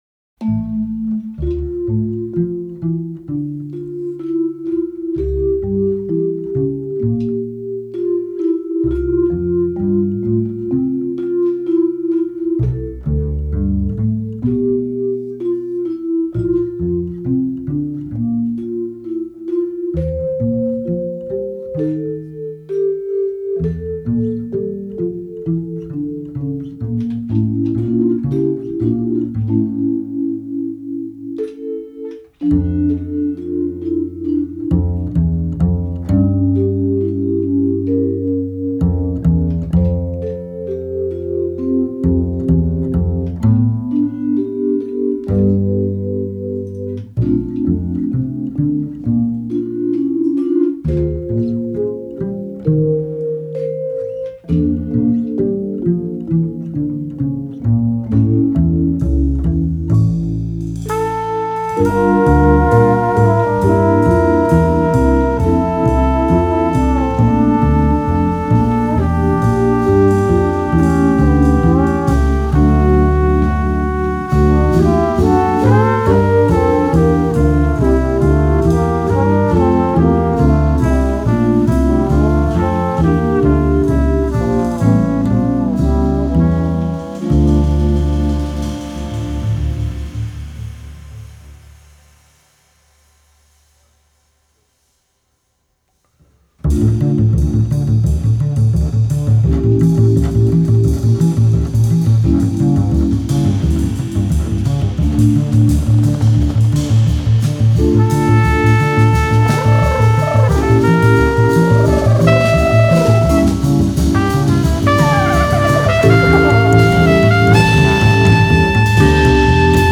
recorded back in 2007